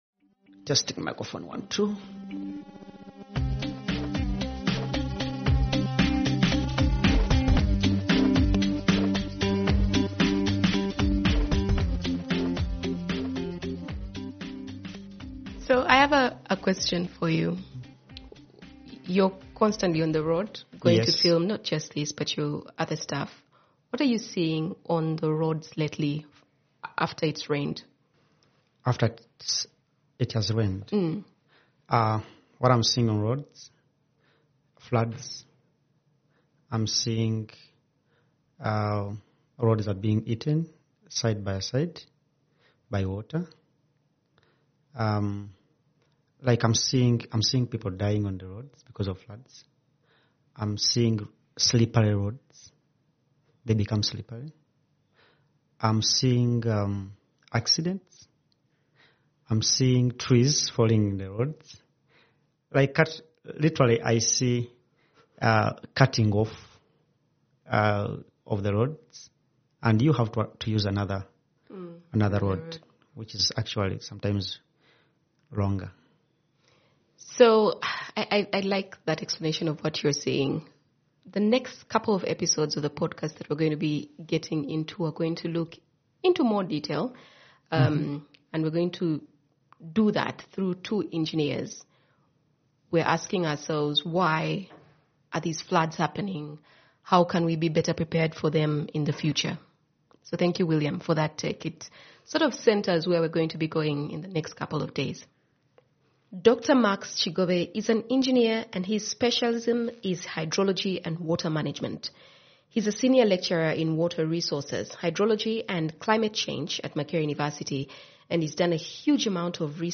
He's en engineer by training and has worked on major infrastructure projects, so he brings an engineer's insights alongside experience in analysing historical patterns of rainfall in the country.